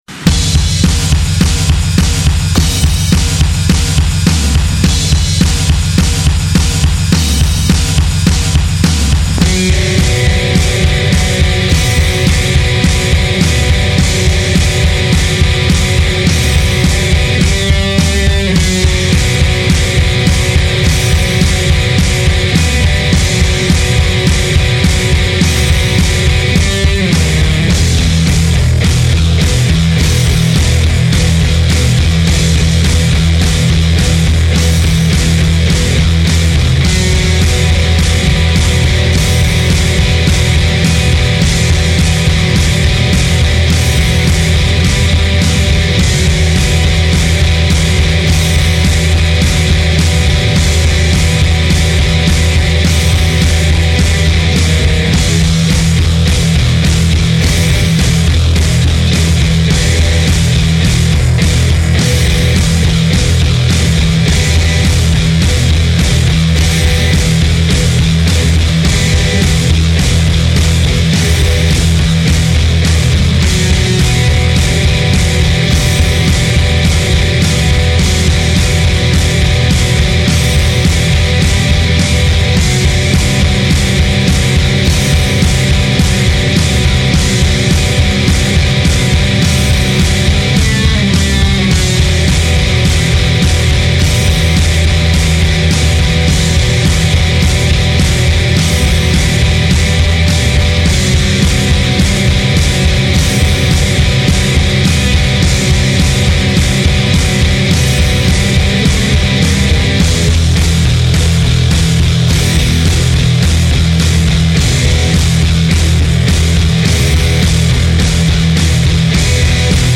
guitare
basse